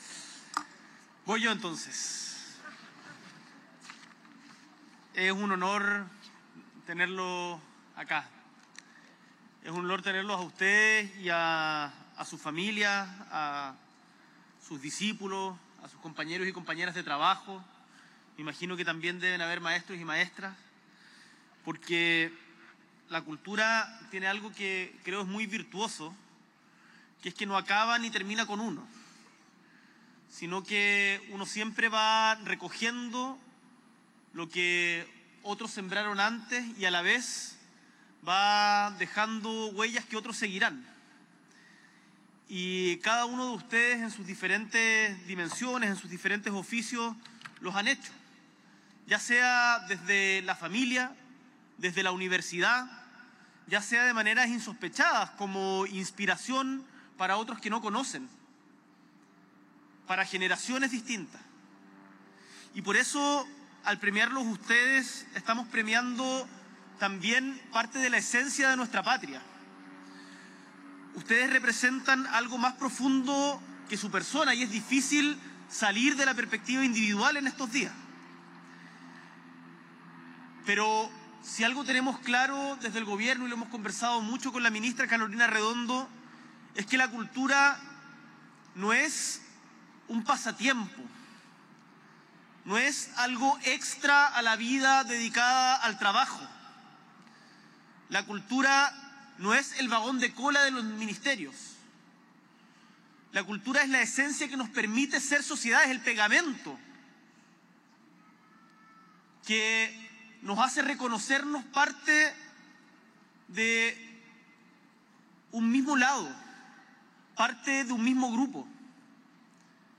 S.E el Presidente de la República, Gabriel Boric Font, encabeza la ceremonia de entrega de los Premios Presidente de la República 2024 a la Música Nacional y a las Artes Escénicas, junto a la ministra de las Culturas, las Artes y el Patrimonio, Carolina Arredondo.
Discurso